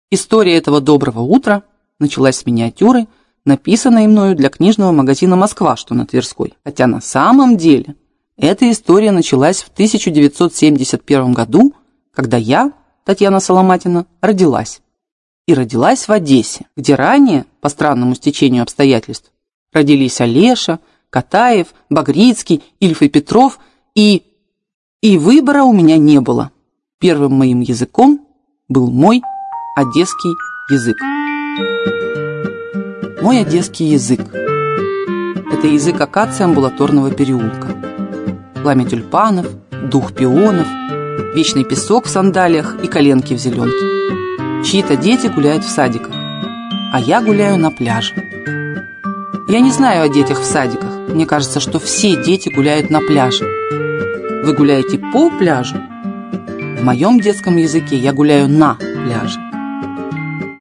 Аудиокнига Доброе утро, Одесса!
Автор Татьяна Соломатина Читает аудиокнигу Татьяна Соломатина.